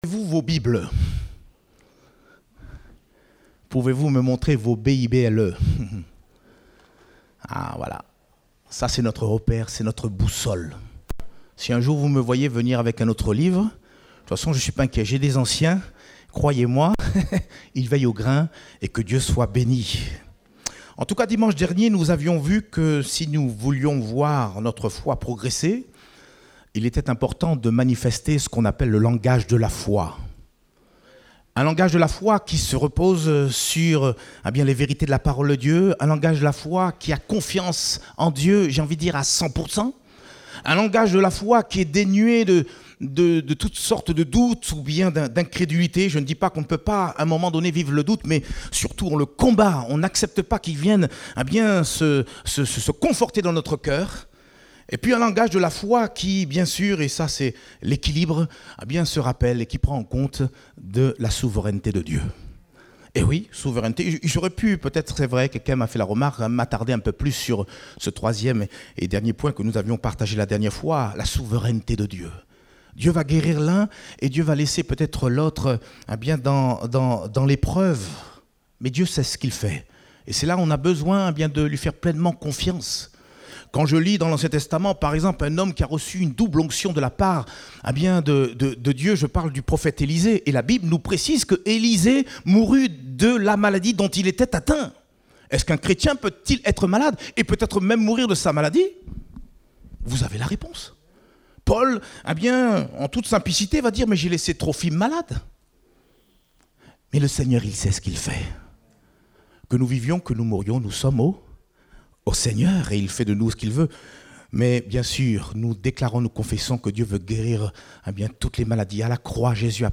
Date : 4 juillet 2021 (Culte Dominical)